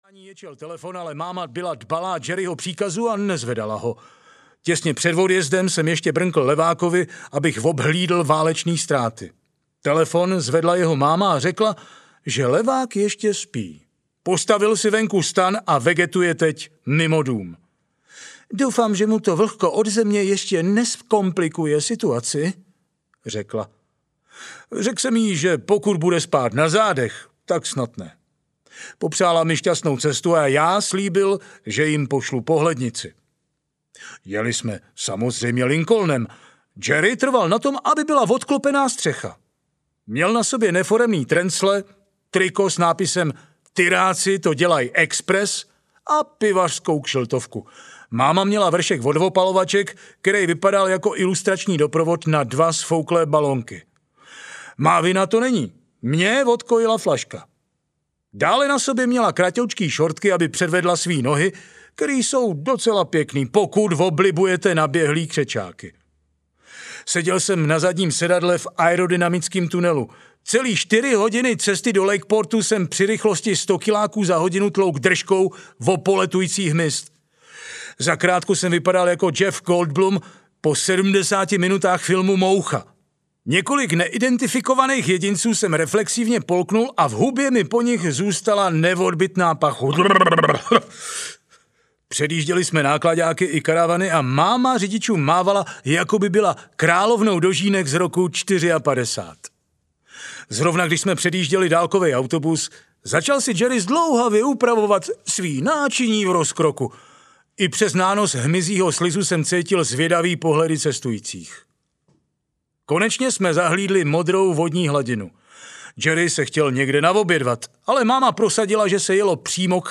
Audio knihaMládí v Hajzlu 1-3
Ukázka z knihy